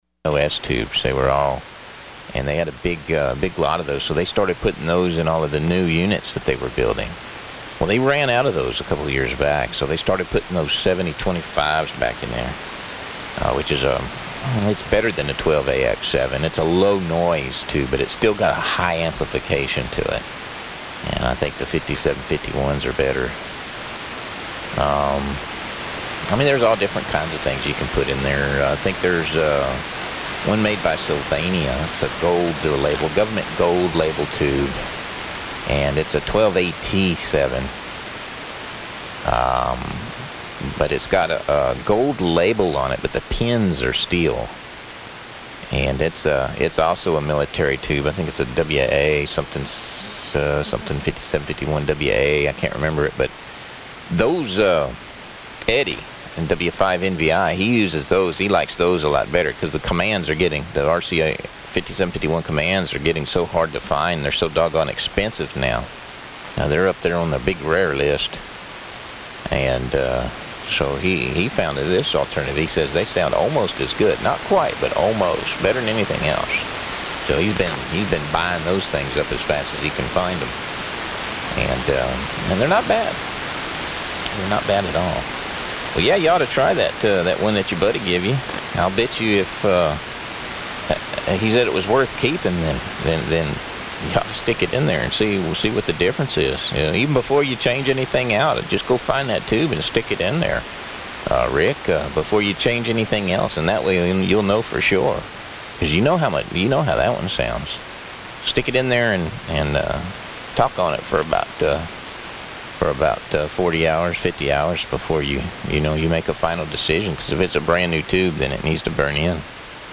Here is another one from a few days ago, again the band conditions were not that great.